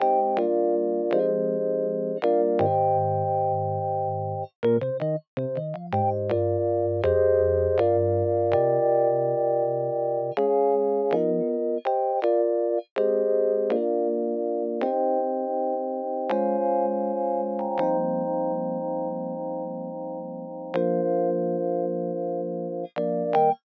11 rhodes C.wav